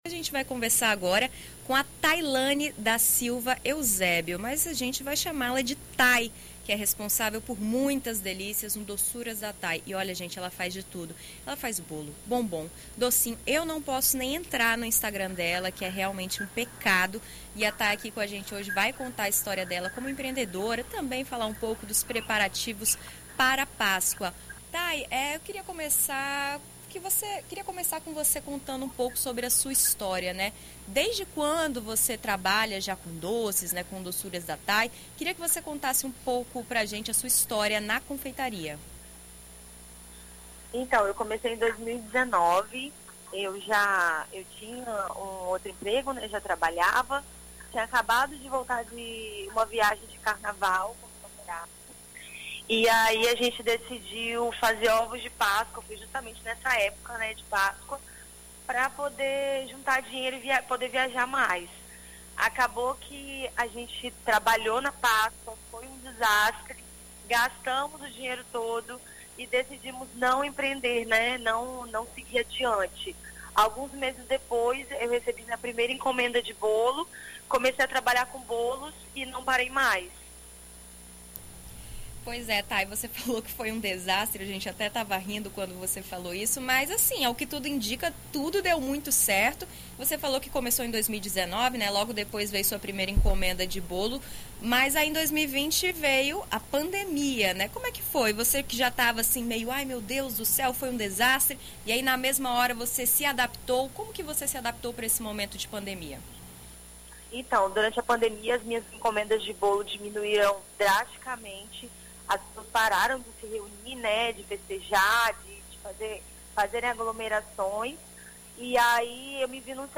Uma doceria criada pela empreendedora, com destaque para a venda dos ovos de páscoa agora em abril. Em entrevista à BandNews FM ES nesta terça-feira (28)